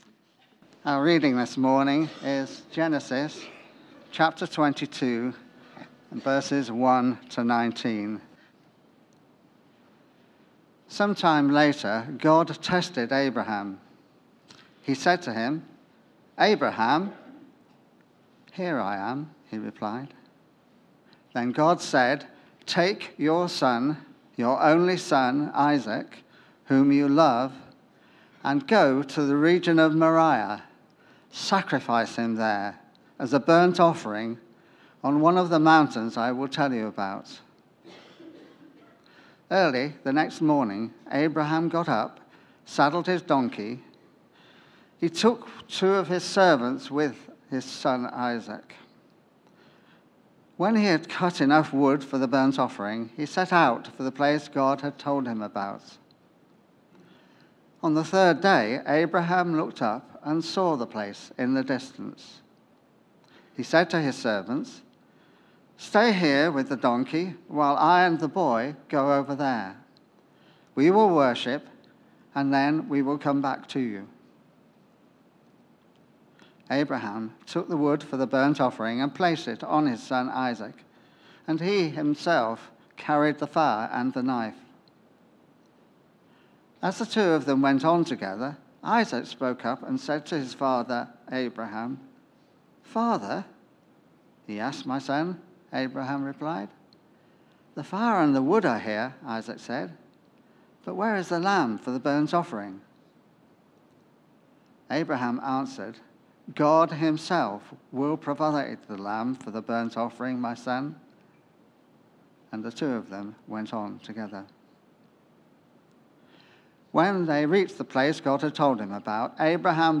Theme: Abraham is tested Sermon